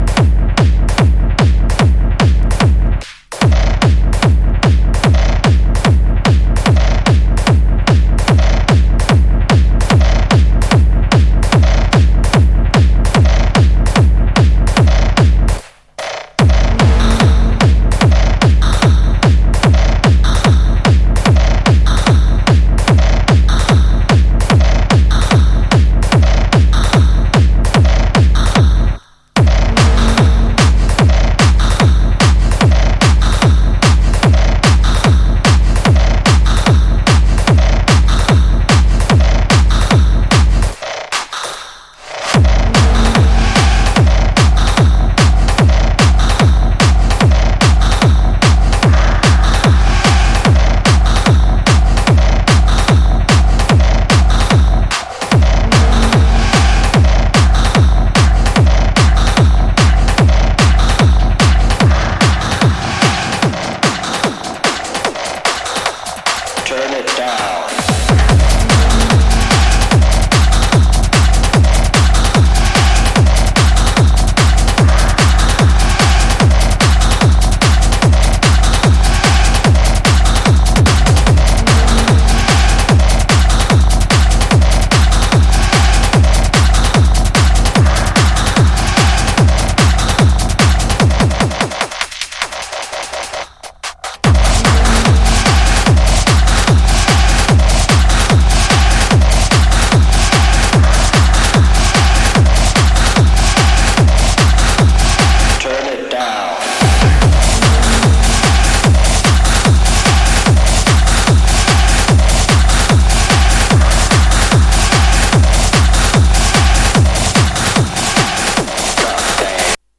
Acid Techno / Hard Techno Lp